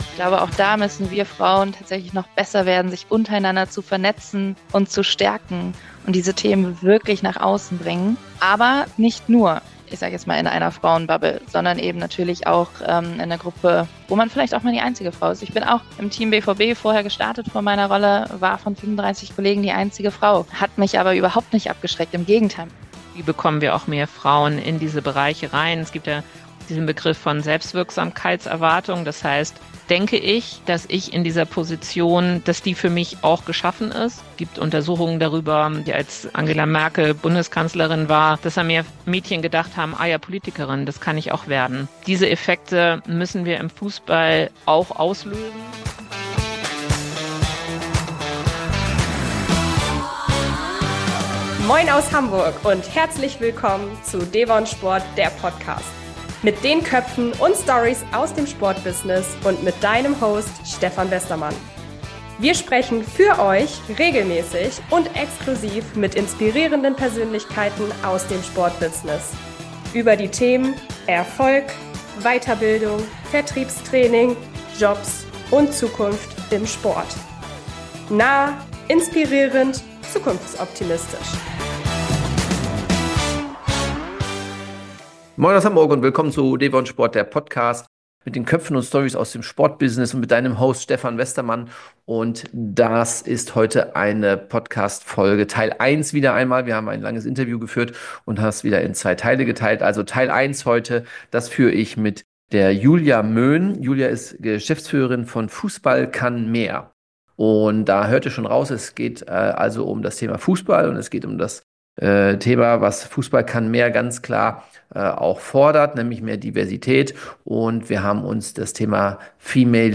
Nur im devonSPORT – Der Podcast hörst du ganz exklusiv, wie es in diesem spannenden Interview weiterging!